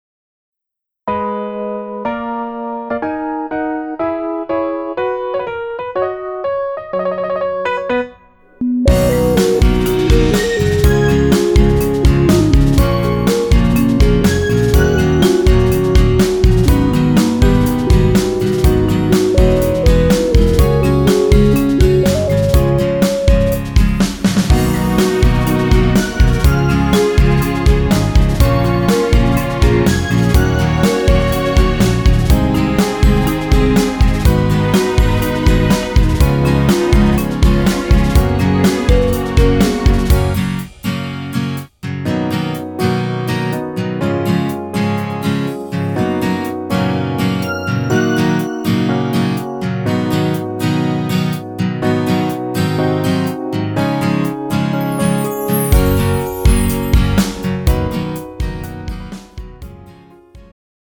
음정 남자-2키
장르 축가 구분 Pro MR